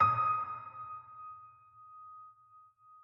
multiplayerpiano - An online piano you can play alone or with others in real-time.